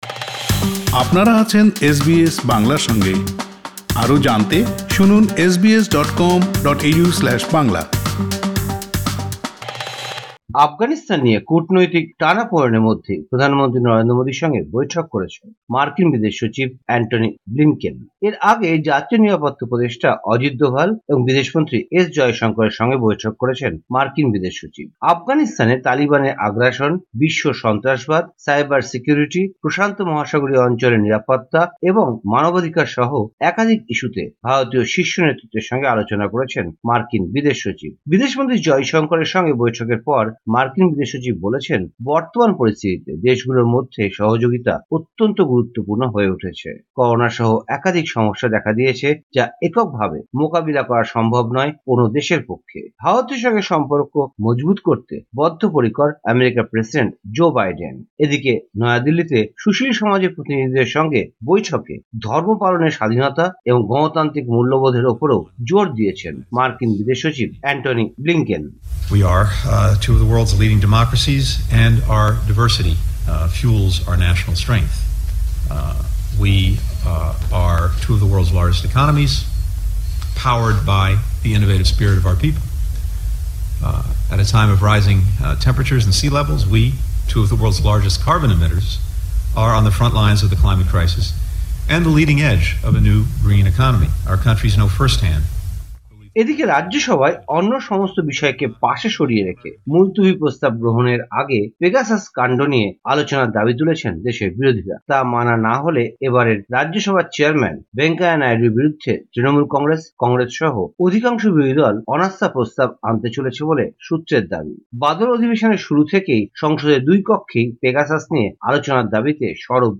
ভারতীয় সংবাদ: ২ আগস্ট ২০২১